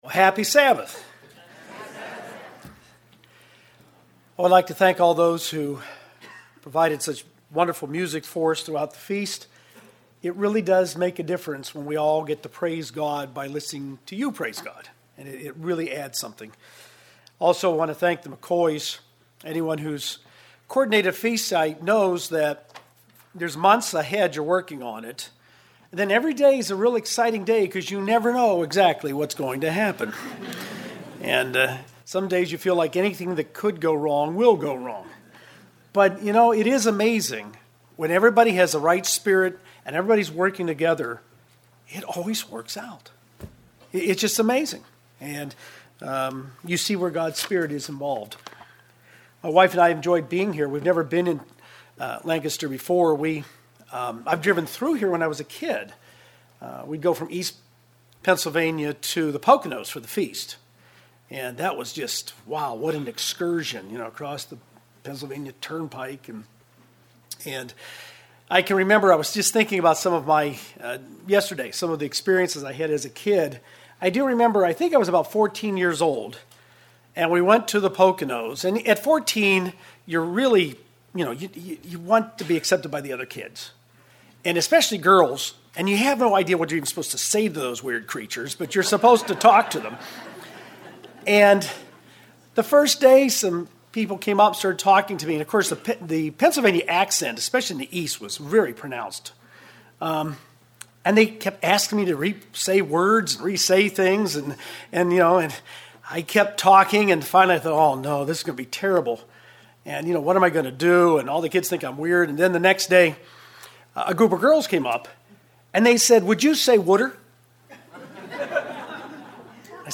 This sermon was given at the Lancaster, Pennsylvania 2019 Feast site.